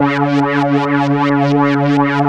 3100 AP  C#4.wav